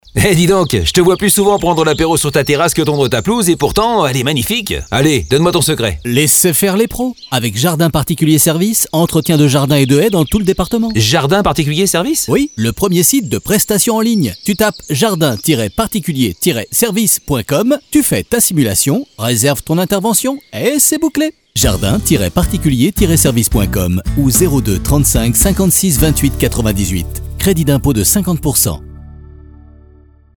starofservice_52510pubradiojardinsparticuliersservices.mp3